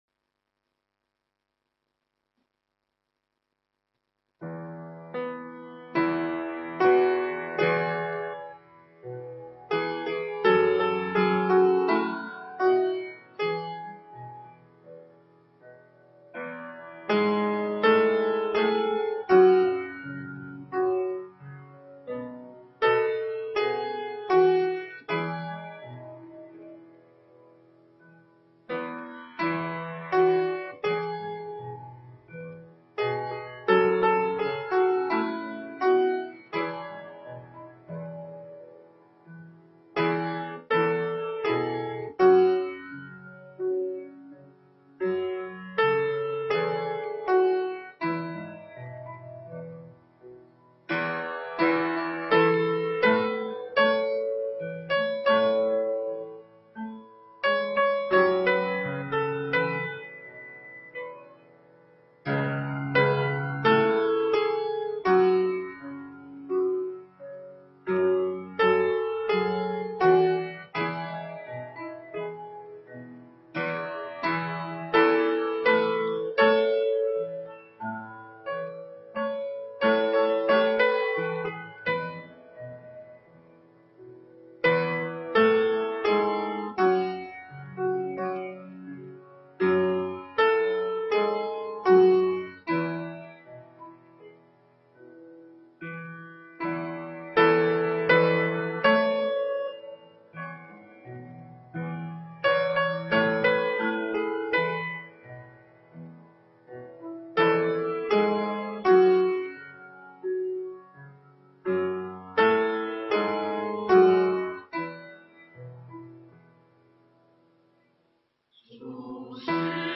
北京基督教会海淀堂